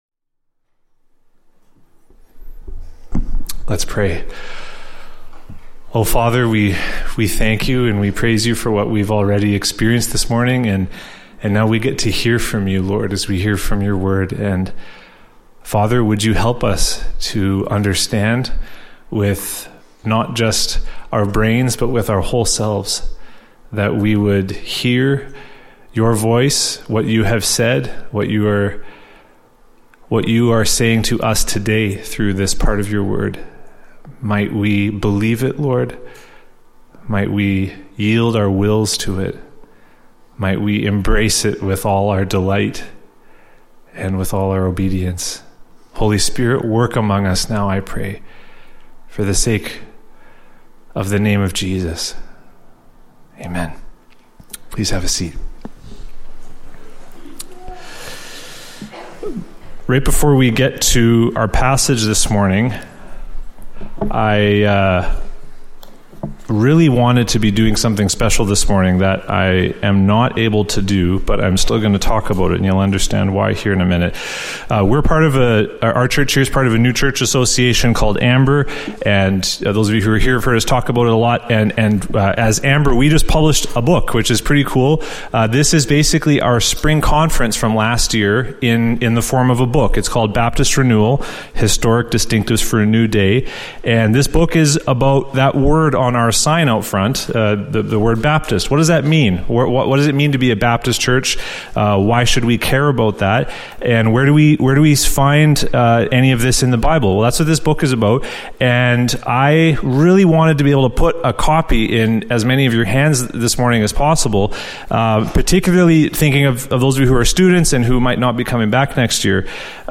I have a slightly similar feeling this morning as I prepare to preach on 1 Samuel 12.